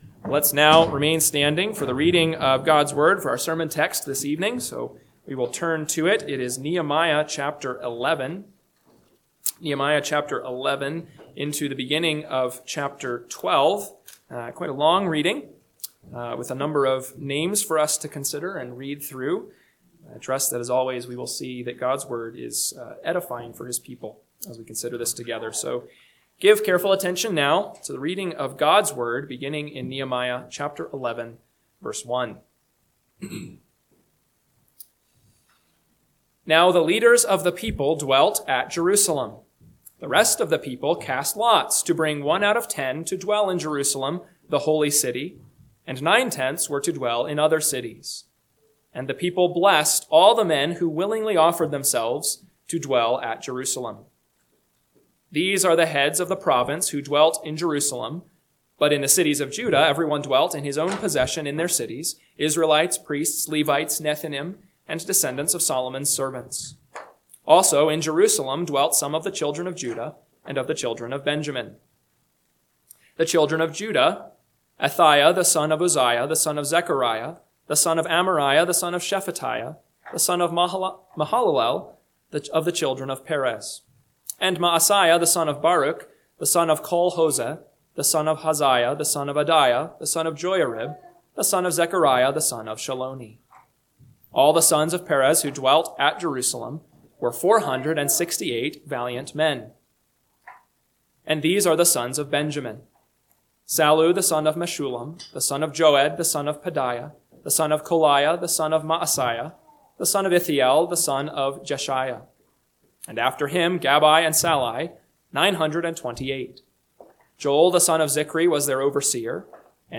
PM Sermon – 11/2/2025 – Nehemiah 11:1-12:26 – Northwoods Sermons